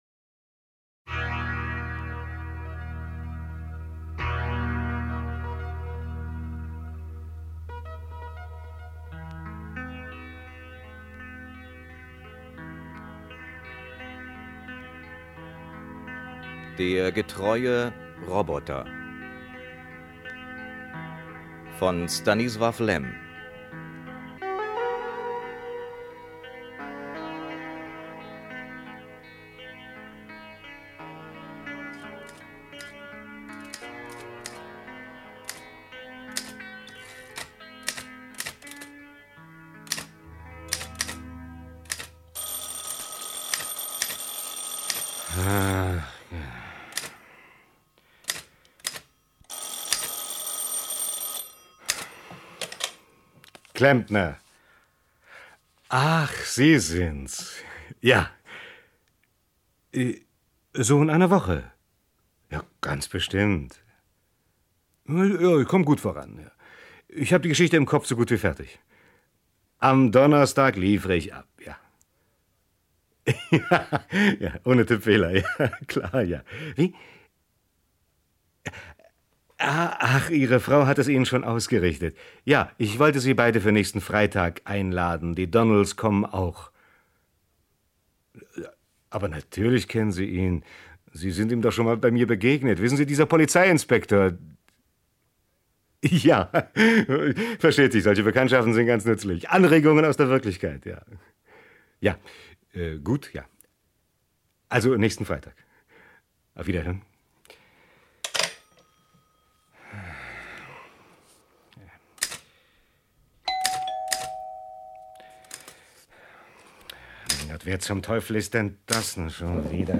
Acht spannend inszenierte Hörspiele über fremde Welten erstmals in einer Box! Mit den absoluten Klassikern der Science-Fiction.